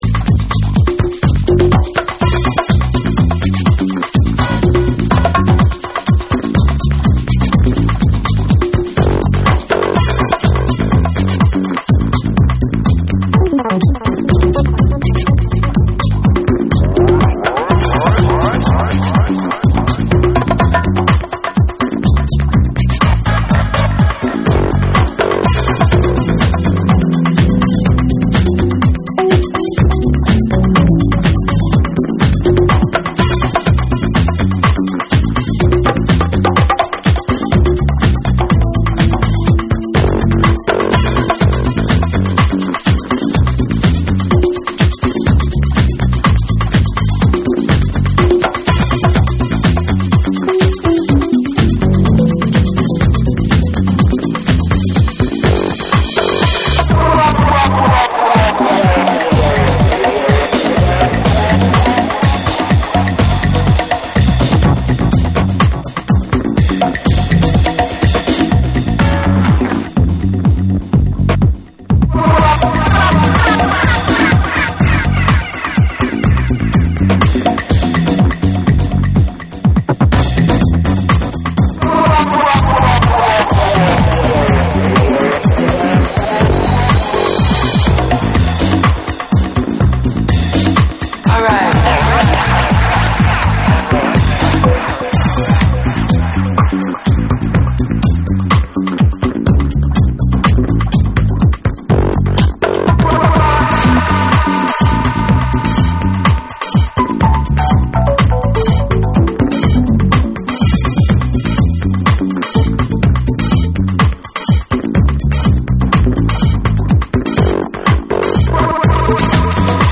TECHNO & HOUSE / DISCO DUB